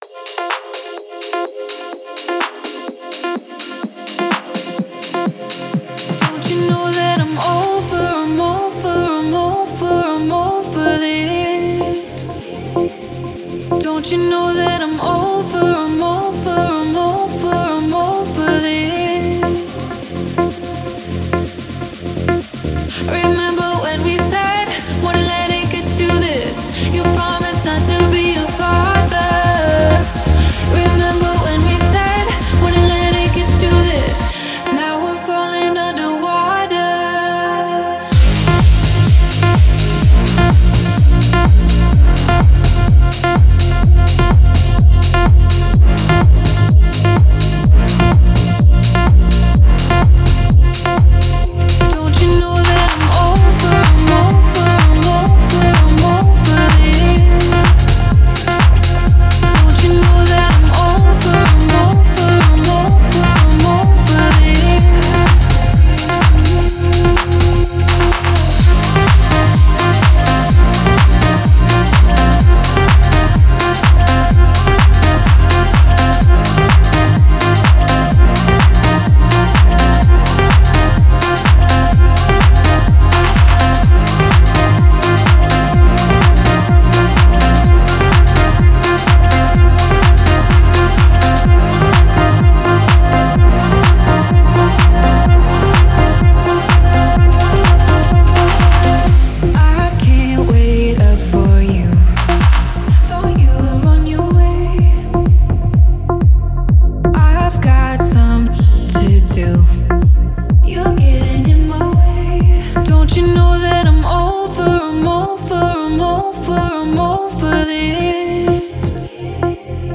это захватывающая электронная танцевальная композиция